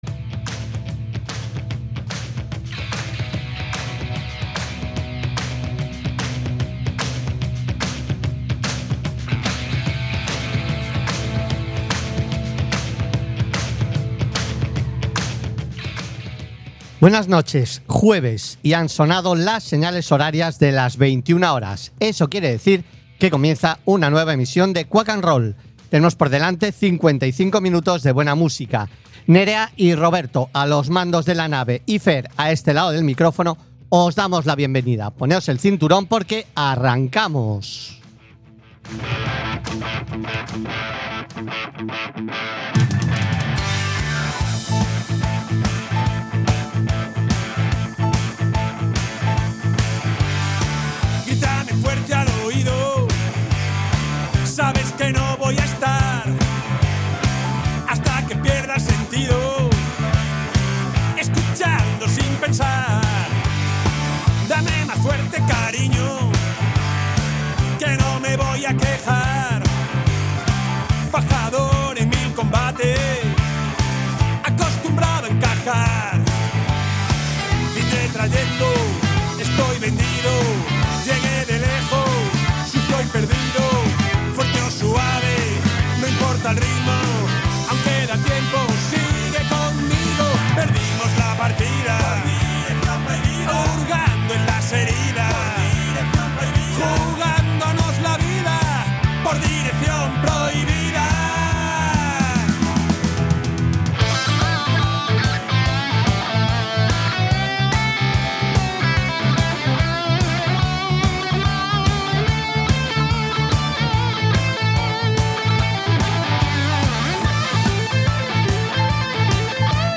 Programa musical que huye de las radioformulas y en el que podreis escuchar diversidad de generos... Rock , Blues , Country, Soul , Folk , Punk , Heavy Metal , AOR...